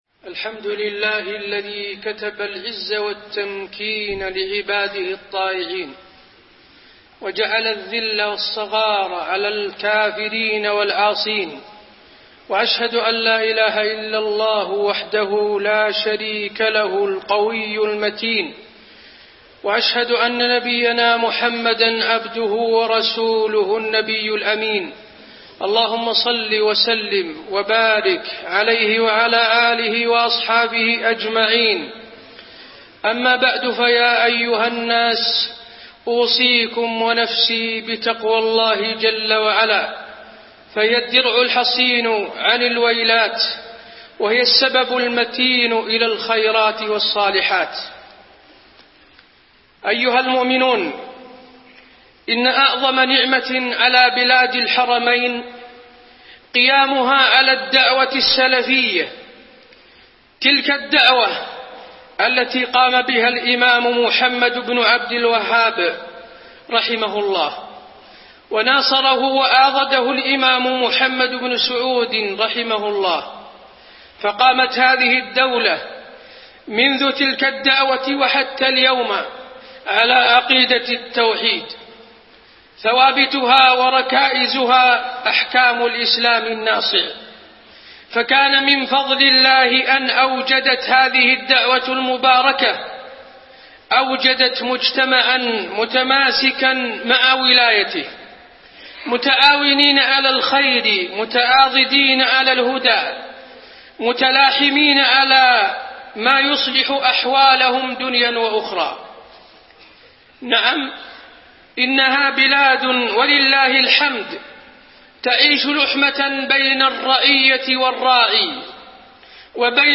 تاريخ النشر ١١ جمادى الأولى ١٤٣٢ هـ المكان: المسجد النبوي الشيخ: فضيلة الشيخ د. حسين بن عبدالعزيز آل الشيخ فضيلة الشيخ د. حسين بن عبدالعزيز آل الشيخ وقفات وحقائق من الواقع المعاصر